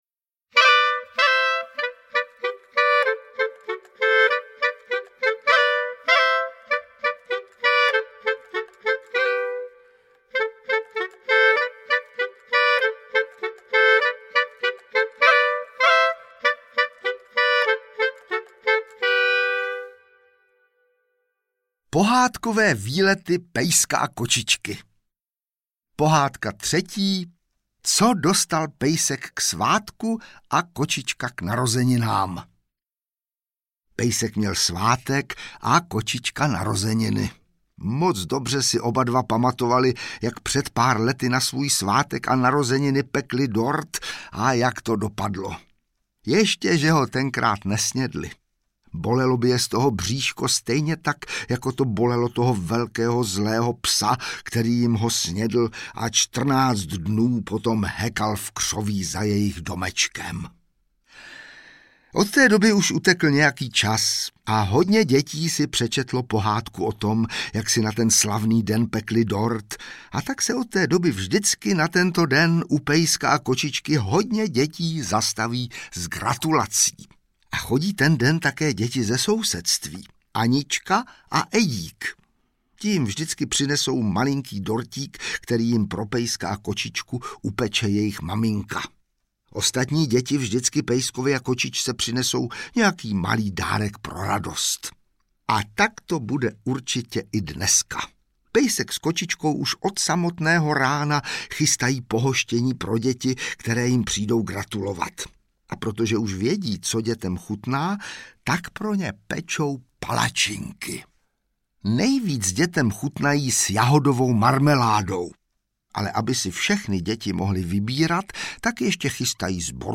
Pohádkové výlety pejska a kočičky audiokniha
Ukázka z knihy
• InterpretVáclav Vydra